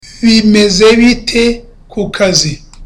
(Curious)